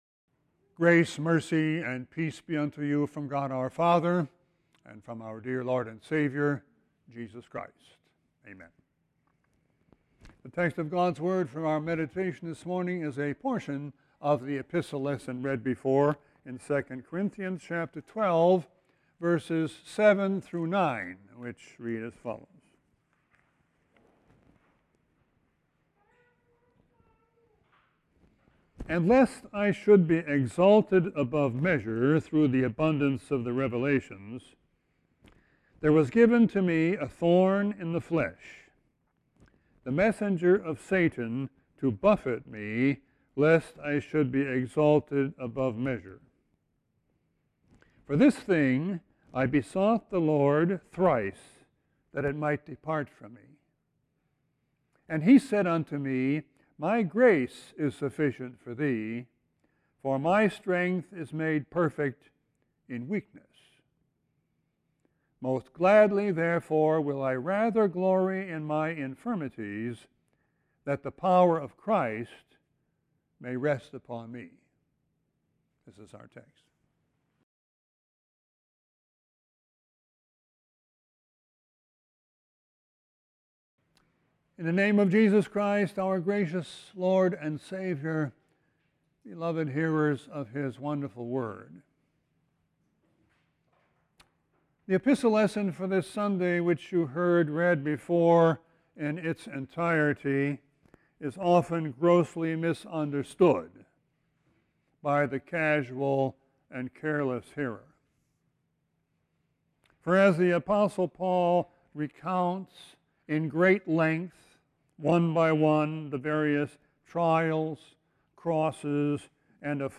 Sermon 2-16-20.mp3